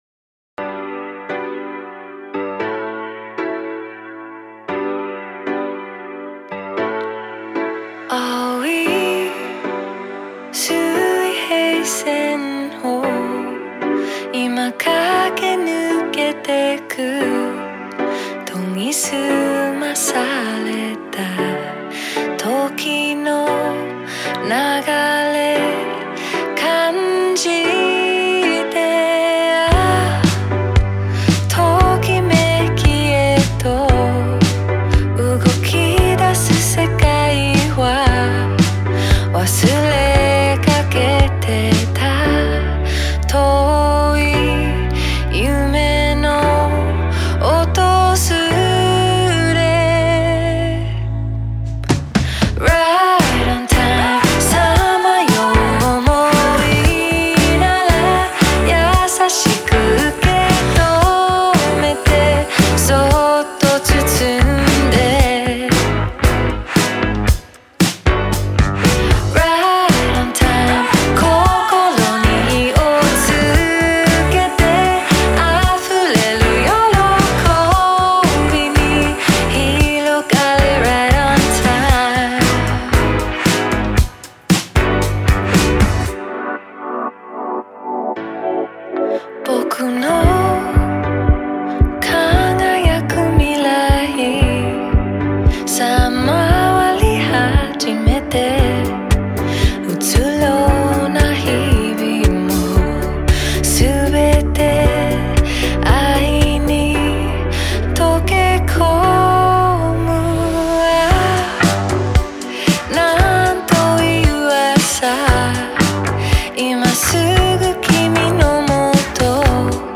ジャンル(スタイル) BLUE EYED SOUL / POP